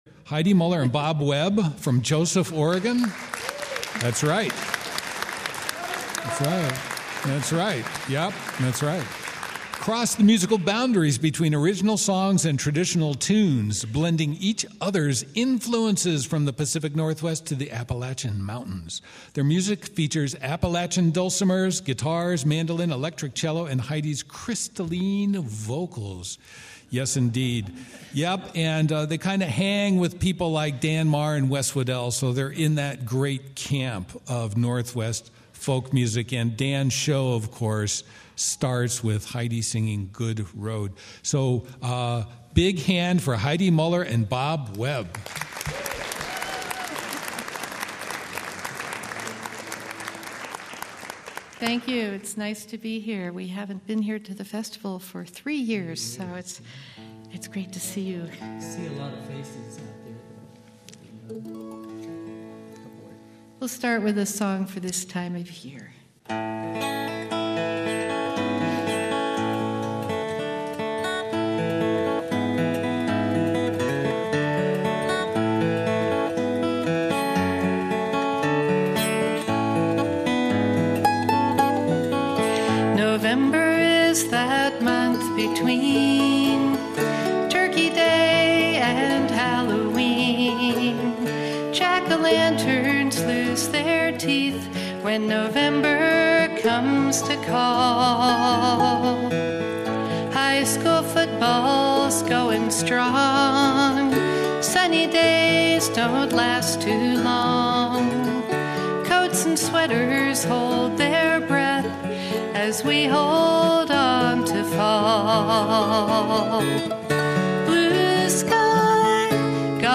Live at Spokane Fall Folk Festival 2018